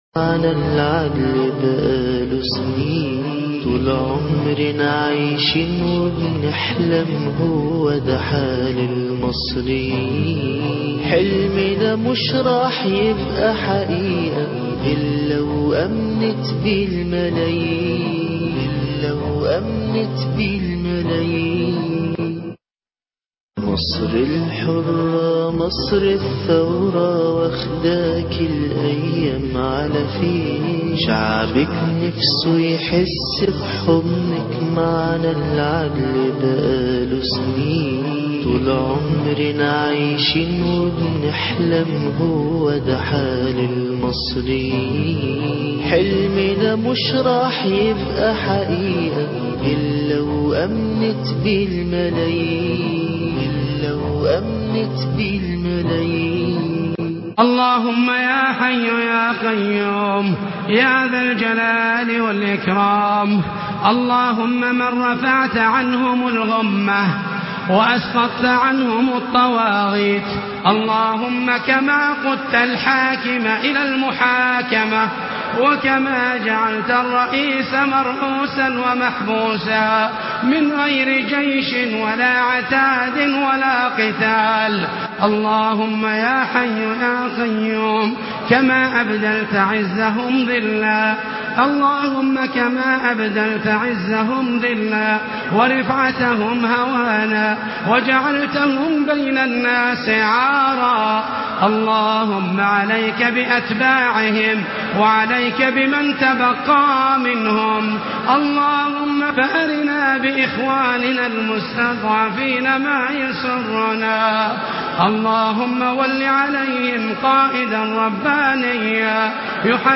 لقاء مطول مع الشيخ حازم ابو اسماعيل وتأييد معظم شيوخ الدعوة السلفية له(4-3-2012)مصر الحرة - الشيخ حازم صلاح أبو إسماعيل